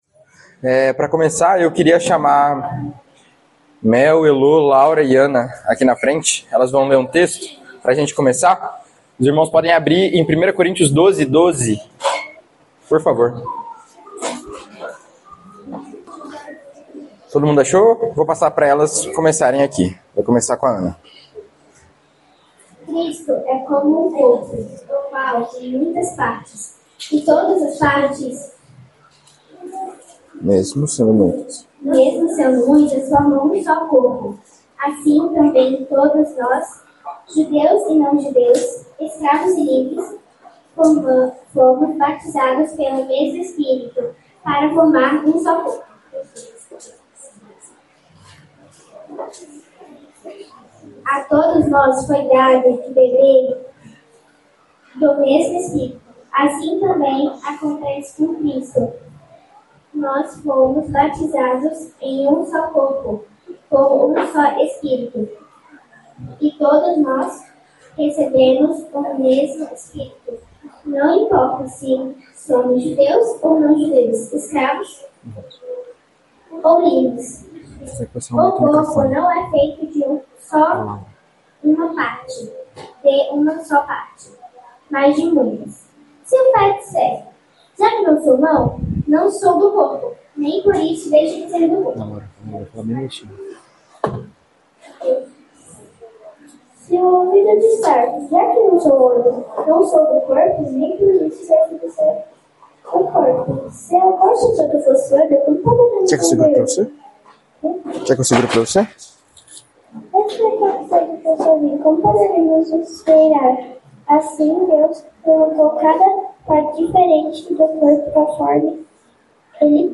Palavra ministrada